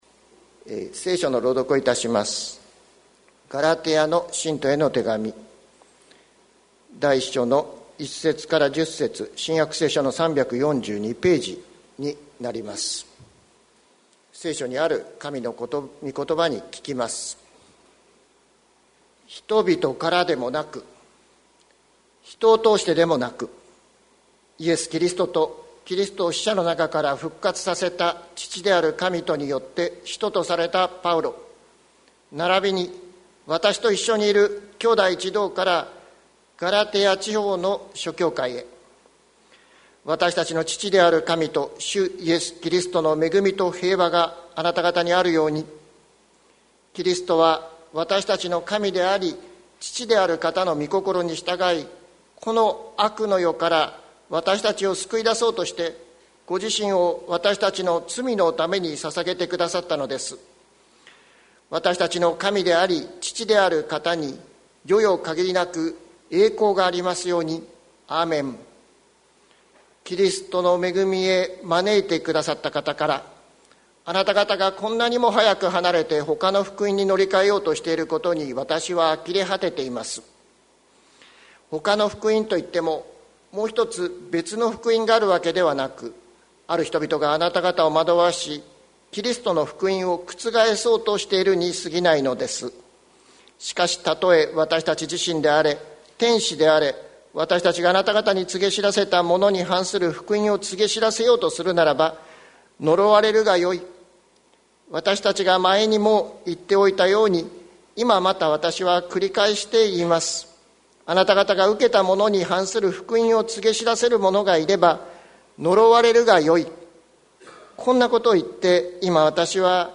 2021年04月18日朝の礼拝「わたしたちの受けた福音」関キリスト教会
説教アーカイブ。